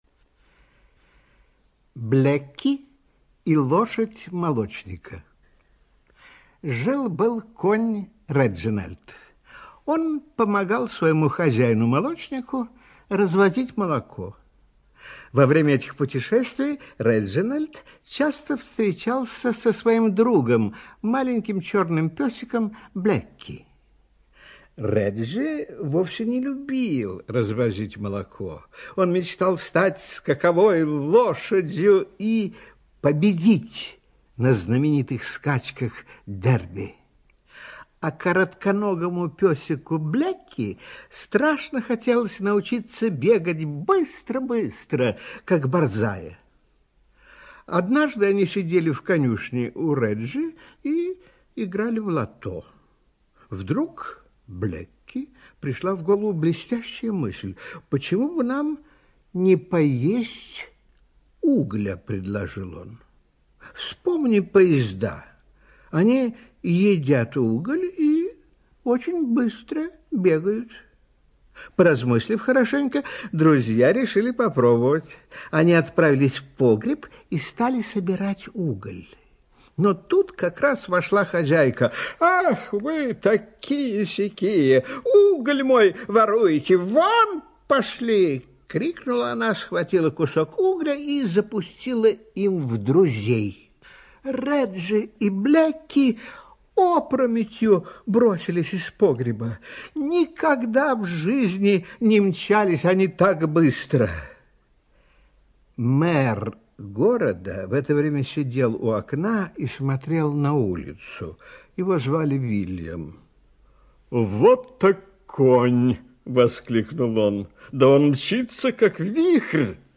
Блэки и лошадь молочника - аудиосказка Биссета. Сказка о том, как собака и лошадь получили медали за скорость…